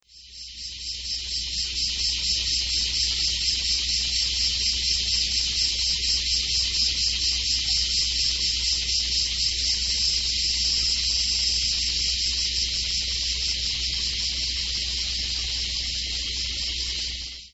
セミだらけの柿畑
真夏の柿畑。
セミだらけ。
kumazemi-1.mp3